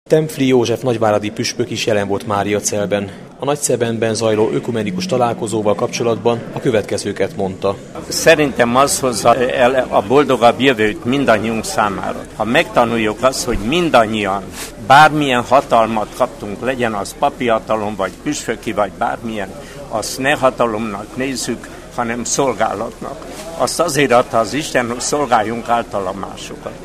Tempfli József nagyváradi püspök így vélekedett a máriacelli úttal párhuzamosan zajló nagyszebeni ökumenikus nagygyűlésről